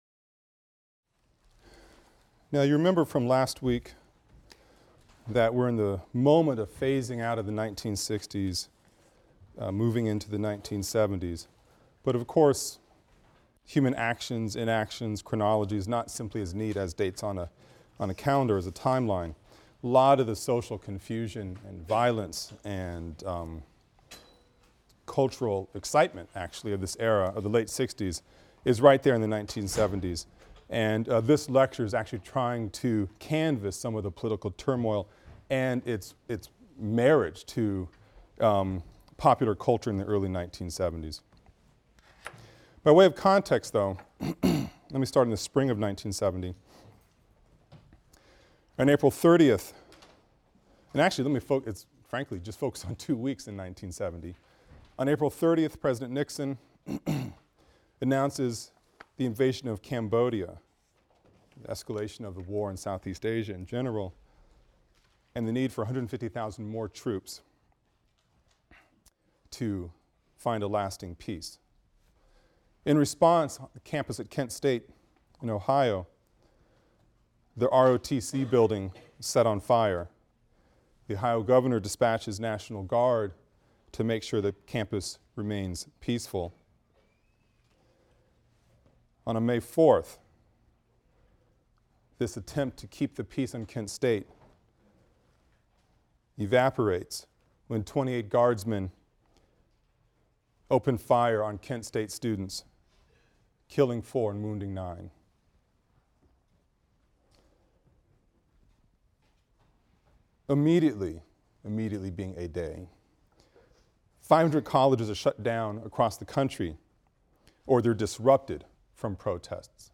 AFAM 162 - Lecture 20 - The Politics of Gender and Culture | Open Yale Courses